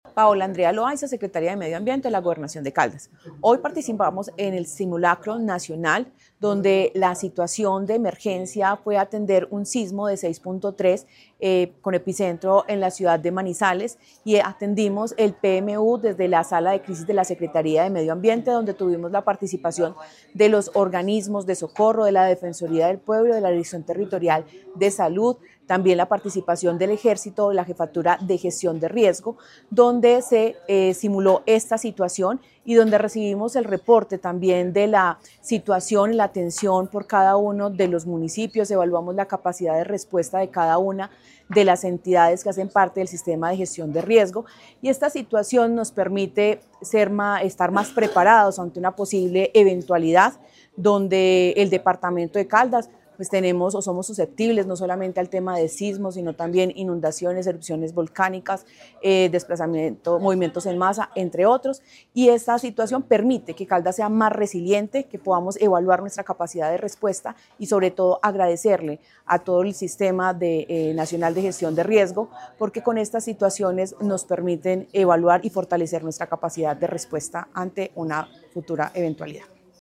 Paola Andrea Loaiza Cruz, secretaria de Medio Ambiente de Caldas.